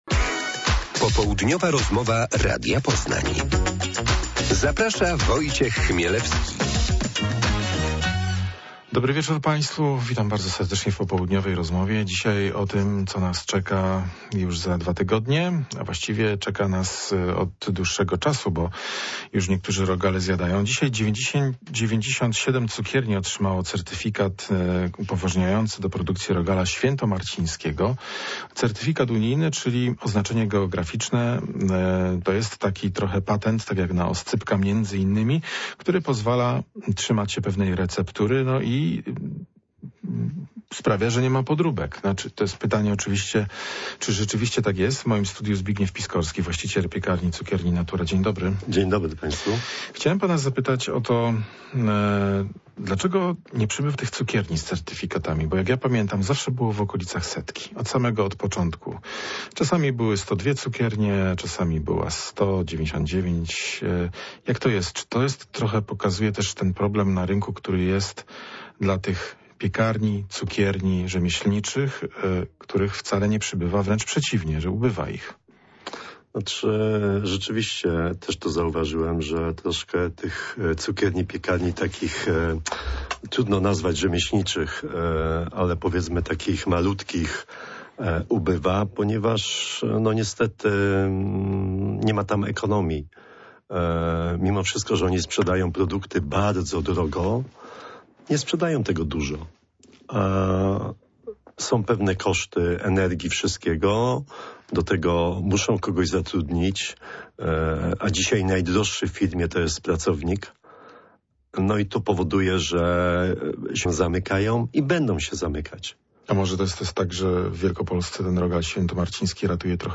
Popołudniowa rozmowa Radia Poznań – Certyfikaty rogalowe